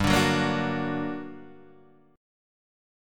G Minor Major 7th